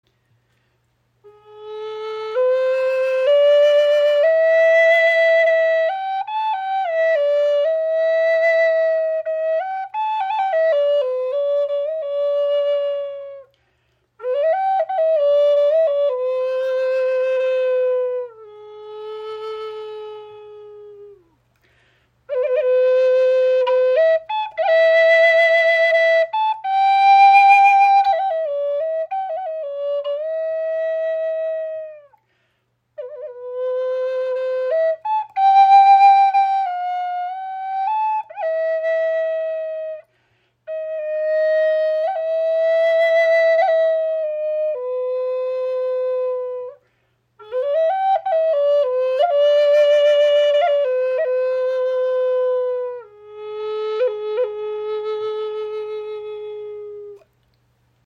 • Icon Klarer, warmer Klang – ideal für Einsteiger
• Icon Aus hartem Walnuss , erzeugt eine klare Klangfarbe
Die Sparrow Hawk Flöte in A-Moll (440 Hz) wird aus Walnussholz handgefertigt.
High Spirits Flöten sind Native American Style Flutes.